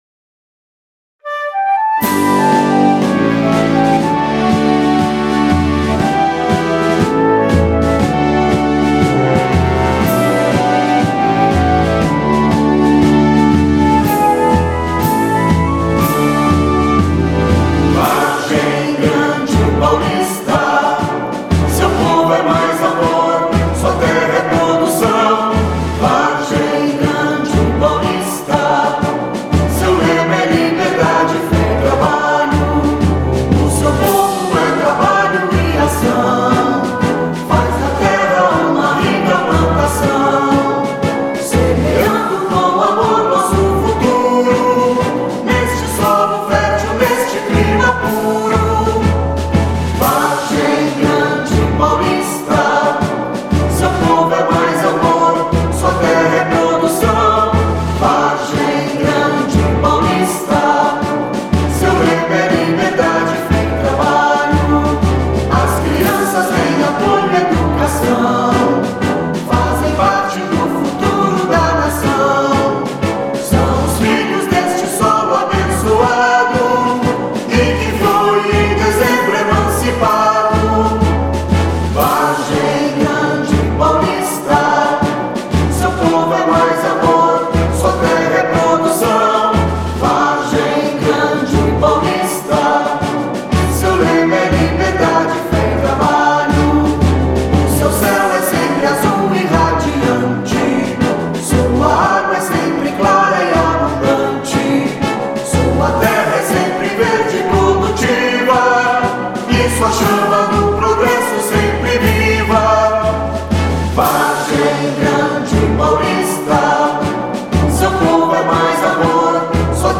Hino